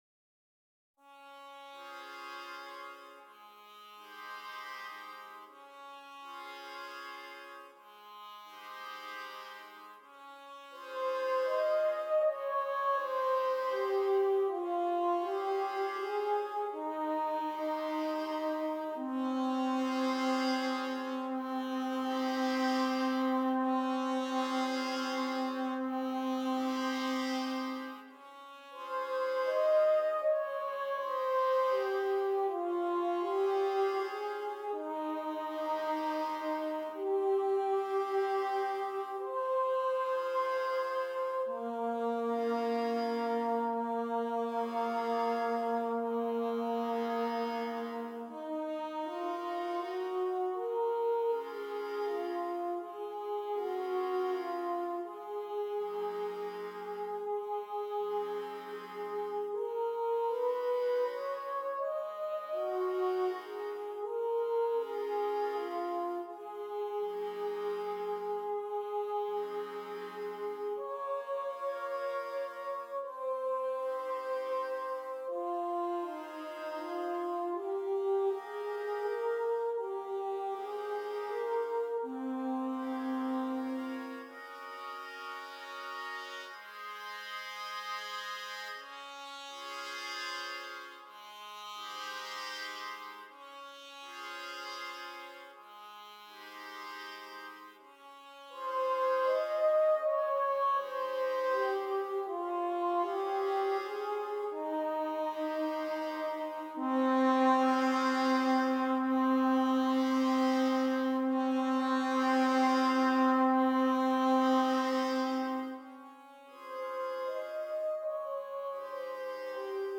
5 Trumpets